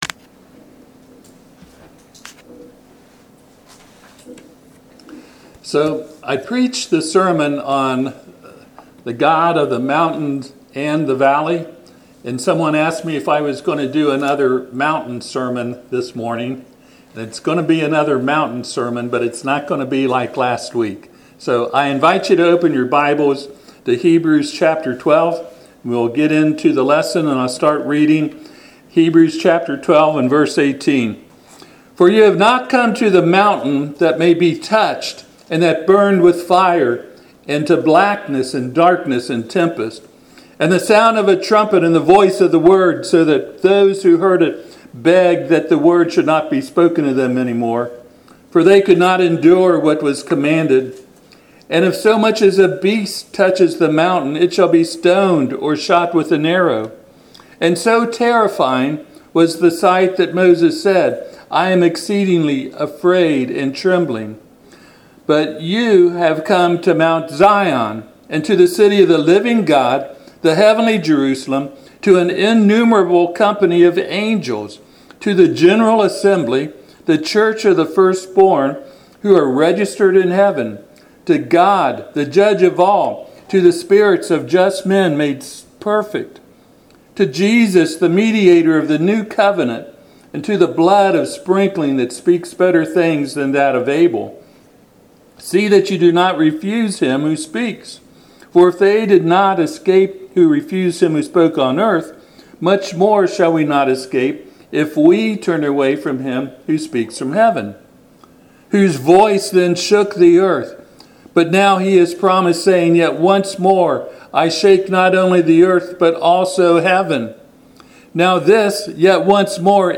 Passage: Hebrews 12:18-29 Service Type: Sunday AM